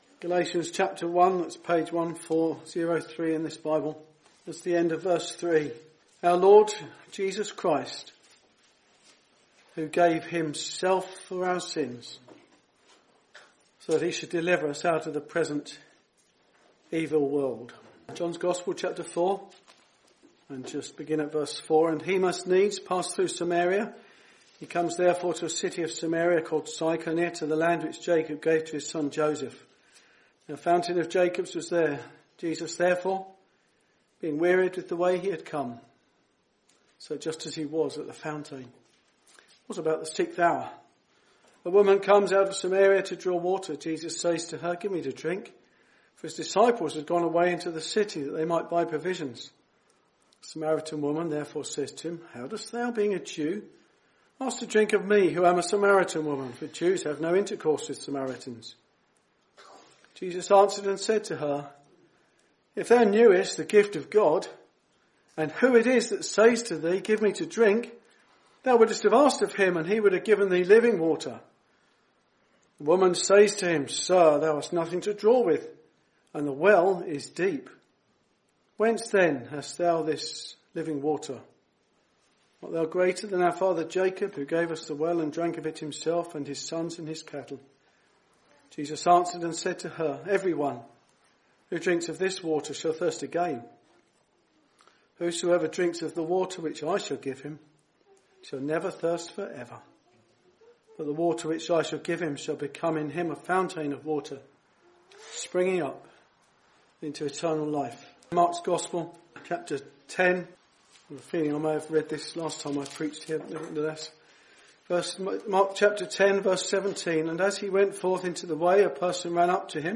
Many people today look for satisfaction in their career, money and possessions but the bible tells us that only Christ can truly satisfy our souls. The Gospel is preached that you may be introduced to the Saviour of the world, the Lord Jesus Christ.